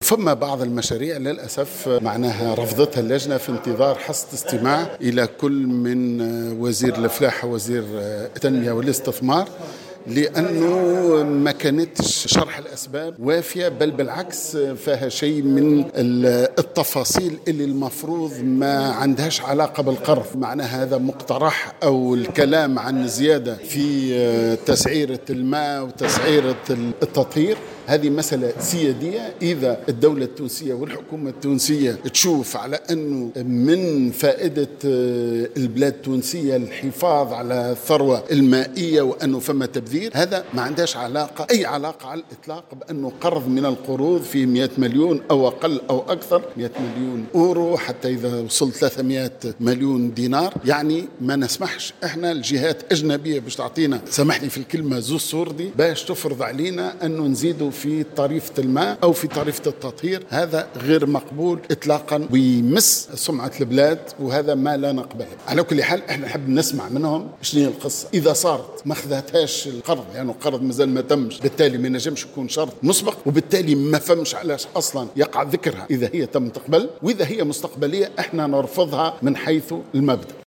وأبرز عضو محمد بن سالم، في تصريح لمراسل الجوهرة أف أم، أن مشروع القانون المذكور، يتعلق باتفاقية قرض بين تونس والمؤسسة الألمانية للقروض من أجل إعادة الإعمار، بقيمة 100 مليون أورو (340 مليون دينار) لتمويل برنامج الإصلاحات في قطاع المياه، تلتزم تونس بموجبه بتنفيذ 13 إصلاحا، من بينها الترفيع في تسعيرة الماء الصالح للشراب بقيمة 150 مليما للمتر المكعب الواحد، وفي معاليم التطهير بنسبة 8%.